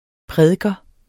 Udtale [ ˈpʁεðəgʌ ]